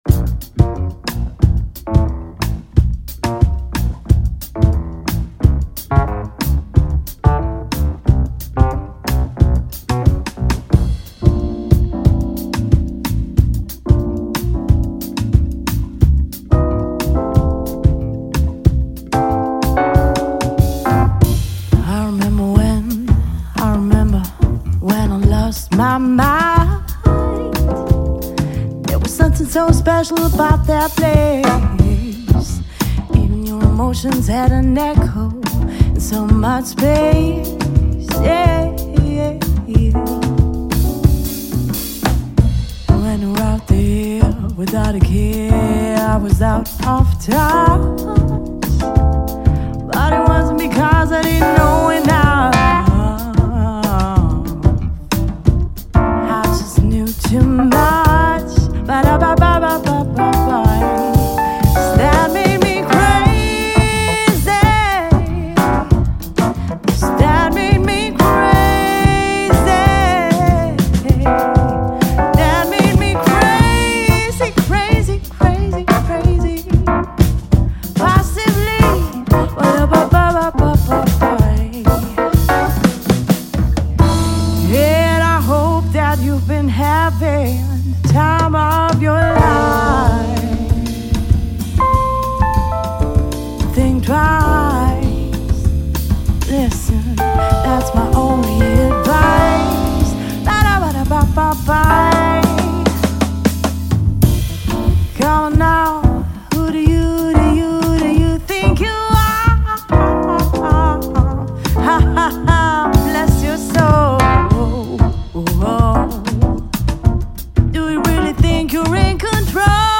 Jazz, Soul und Groove mit spontaner Spielfreude
Gesang
Keyboards
Saxophon
Schlagzeug
Bass